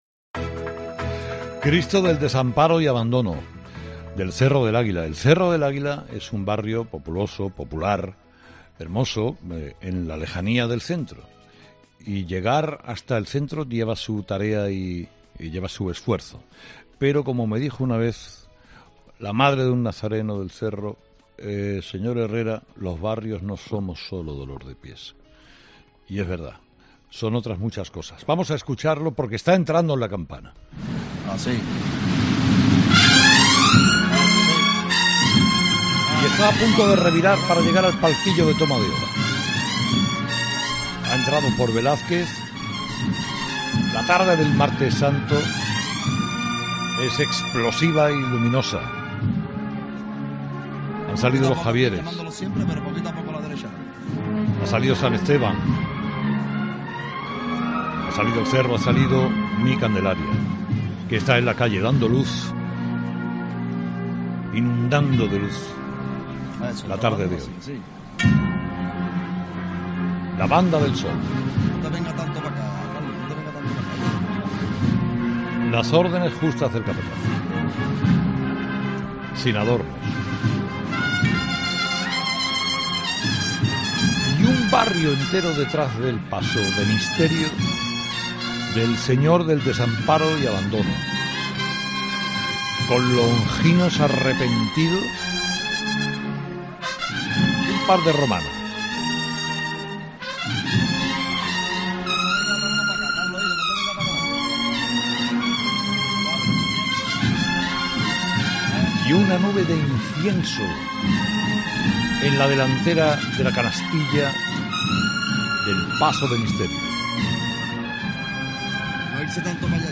Carlos Herrera retransmite la entrada del Cristo del desamparo y abandono del Cerro del Águila en La Campana
En la mañana de hoy ha narrado la entrada del Cristo del Desamparo y Abandono de la hermandad del Cerro del Águia en Campana el pasado Martes Santo de Sevilla